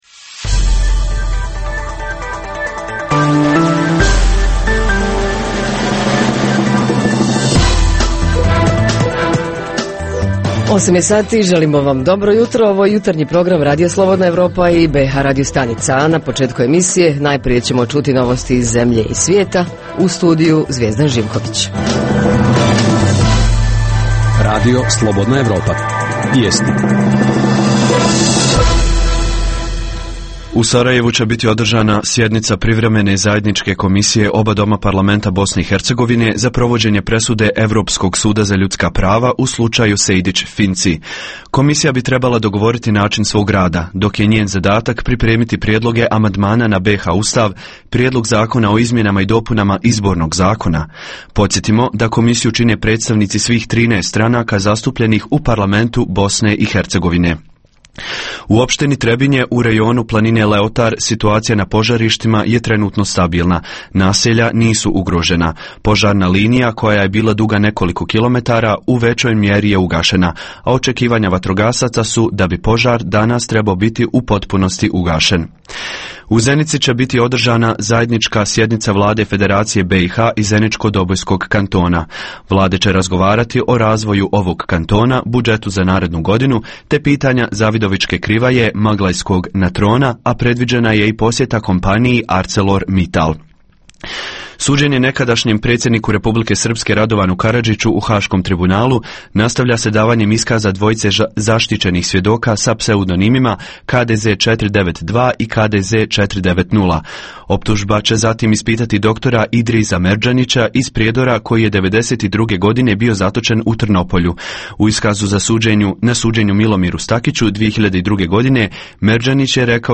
Tema jutarnjeg programa: aktuelni ili gorući lokalni problemi koji muče građane - da li se rješavaju i na koji način? Reporteri iz cijele BiH javljaju o najaktuelnijim događajima u njihovim sredinama.
Redovni sadržaji jutarnjeg programa za BiH su i vijesti i muzika.